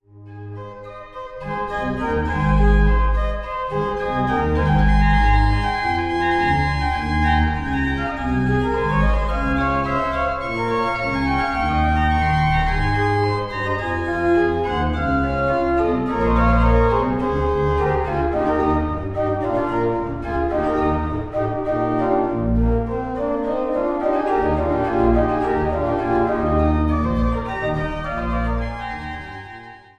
Großengottern, Waltershausen, Altenburg, Eisenach